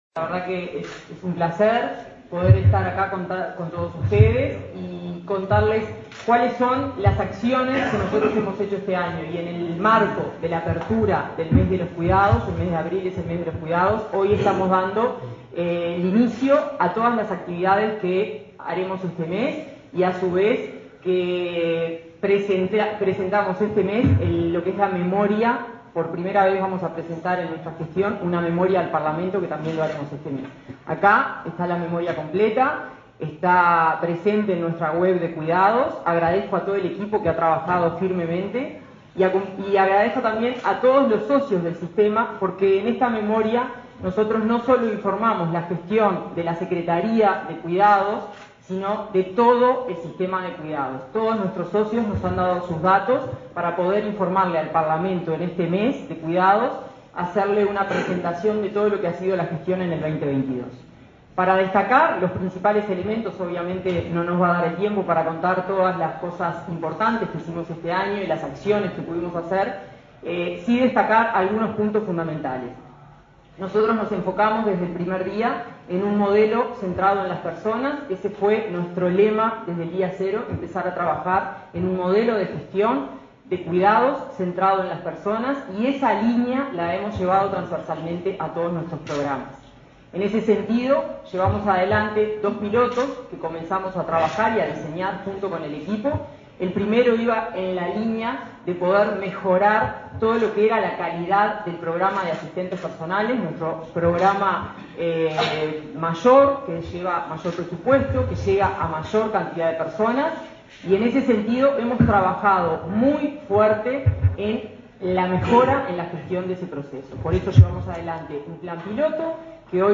Palabras de autoridades del Mides